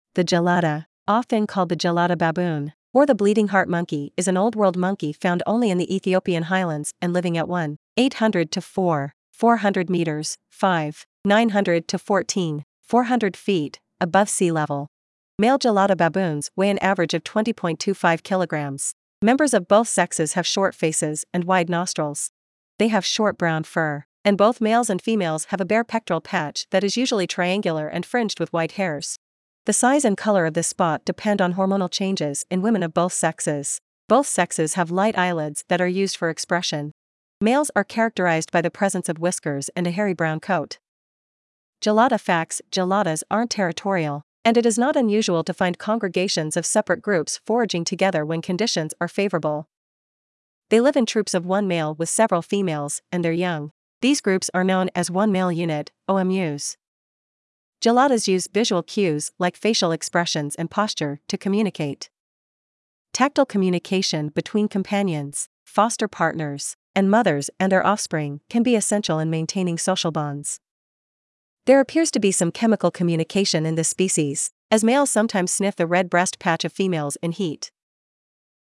Gelada Baboon
Gelada.mp3